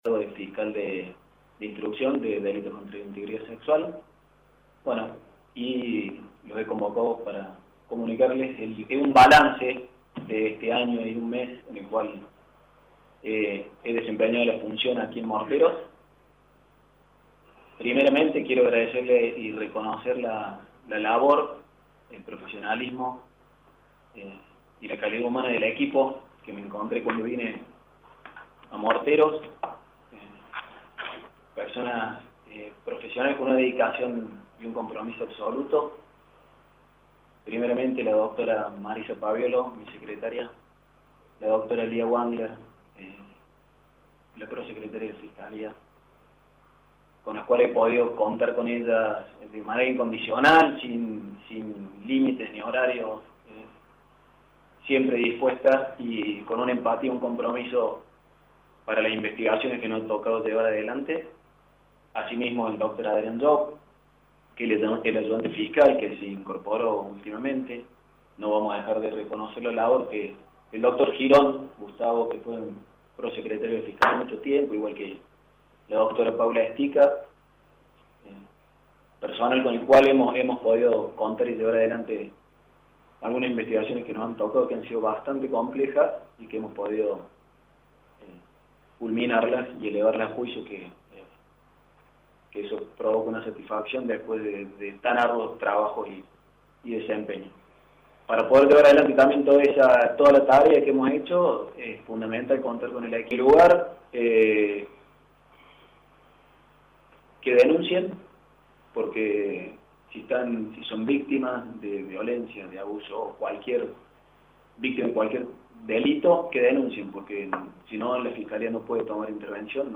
En conferencia de prensa, el Fiscal de Instrucción de Morteros Dr. Juan Fernando Avila Echenique anunció que en las próximas horas asumirá en el cargo de Fiscal de Instrucción de Delitos contra la Integridad Sexual en Córdoba.